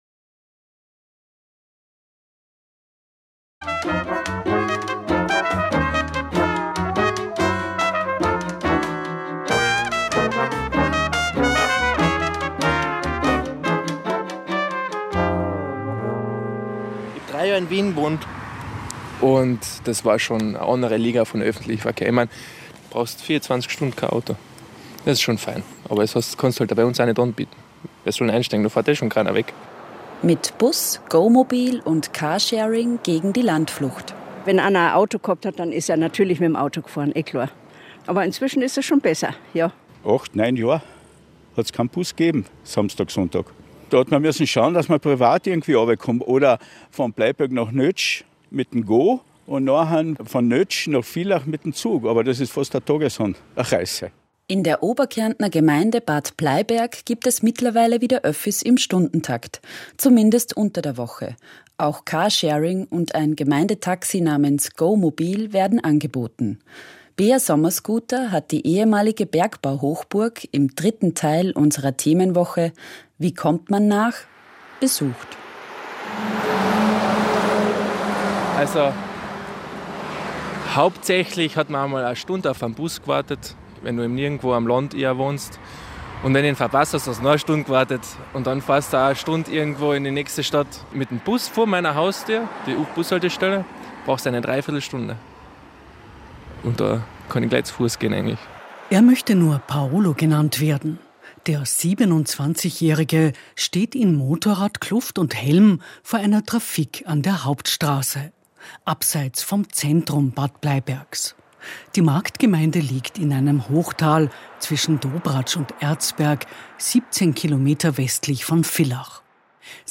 Ausschnitt aus einem Ö1-Beitrag über Mobilität auf dem Land und das GO-MOBIL in Bad Bleiberg